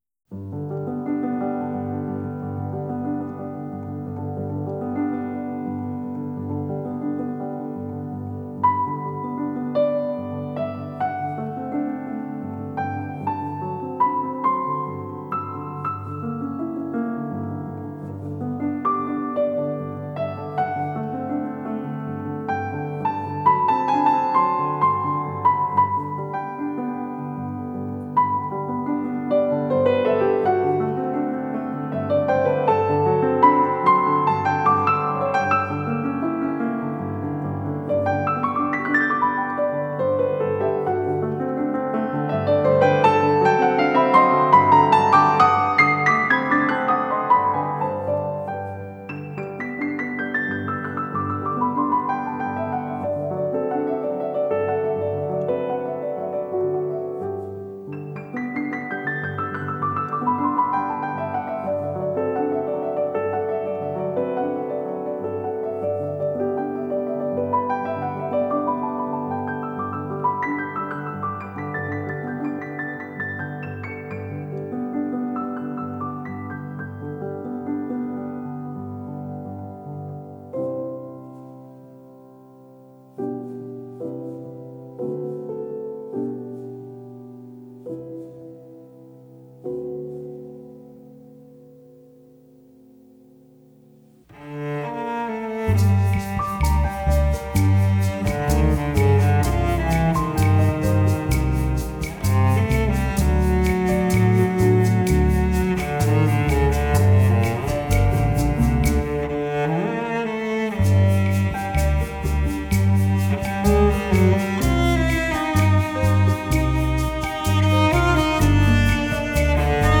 Genre: Jazz
Recorded at Stiles Recording Studio in Portland, Oregon.